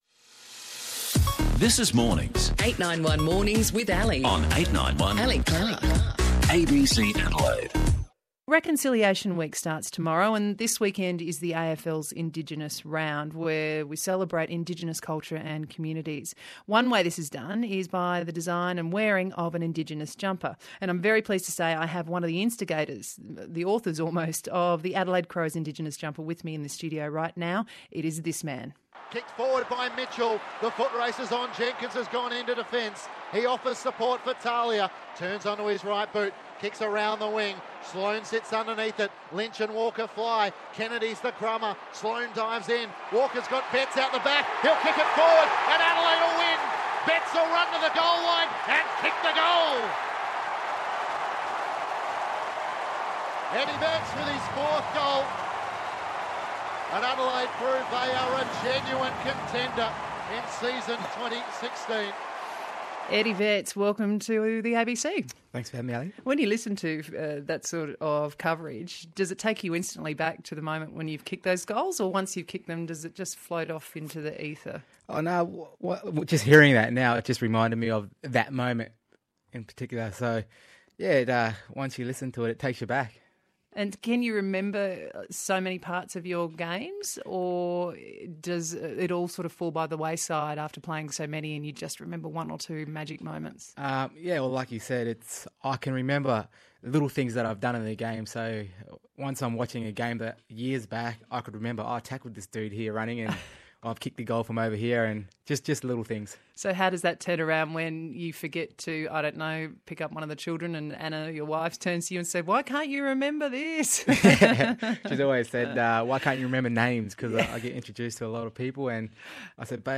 Eddie Betts on ABC radio